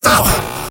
Robot-filtered lines from MvM.
Engineer_mvm_painsharp02.mp3